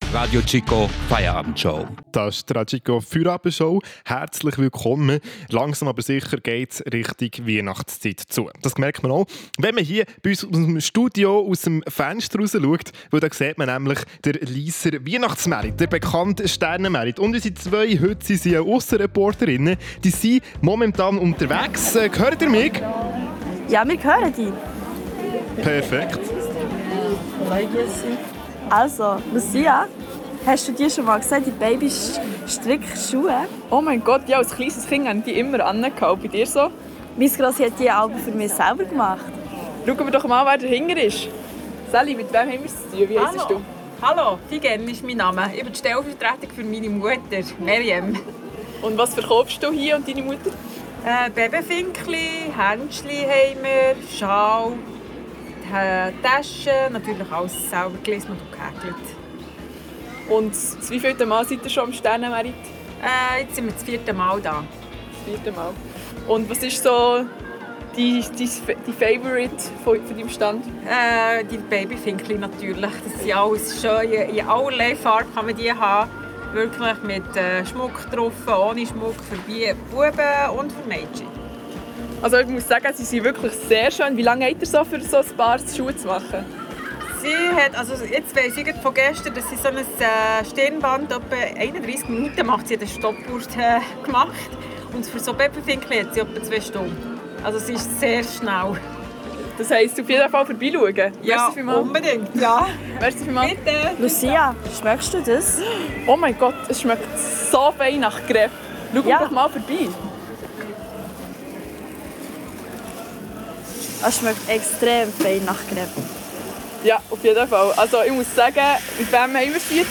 RadioChico war live mit dabei.